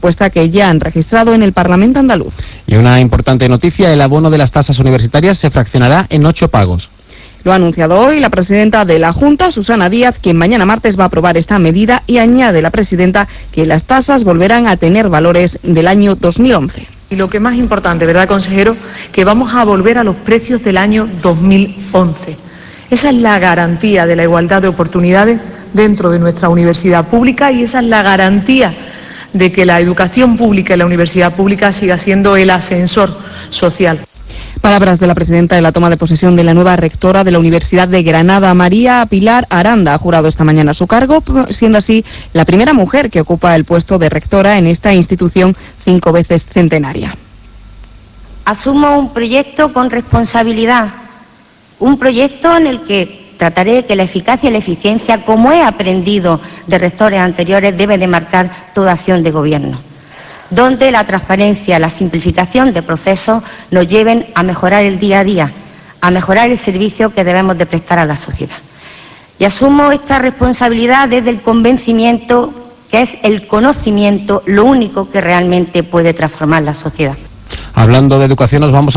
Díaz ha asistido hoy a la toma de posesión de la nueva rectora de la Universidad de Granada, María Pilar Aranda. Decl. Susana Díaz; María Pilar Aranda.